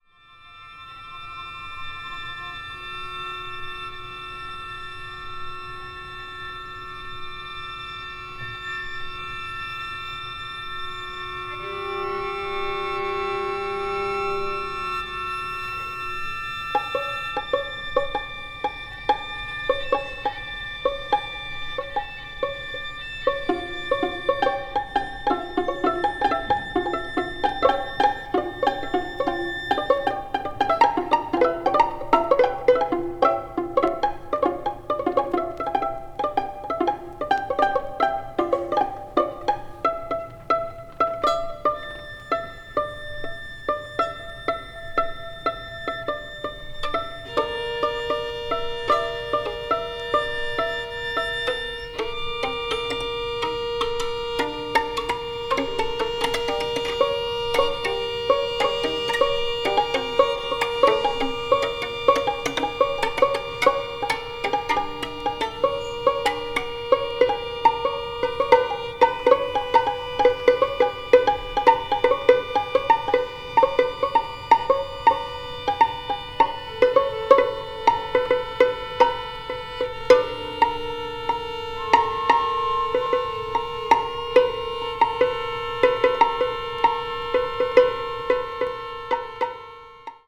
media : EX+/EX(わずかにチリノイズが入る箇所あり)